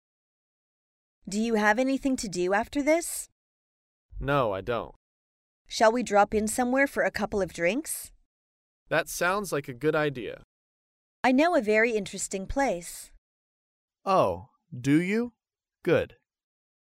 在线英语听力室高频英语口语对话 第357期:邀请同事共饮的听力文件下载,《高频英语口语对话》栏目包含了日常生活中经常使用的英语情景对话，是学习英语口语，能够帮助英语爱好者在听英语对话的过程中，积累英语口语习语知识，提高英语听说水平，并通过栏目中的中英文字幕和音频MP3文件，提高英语语感。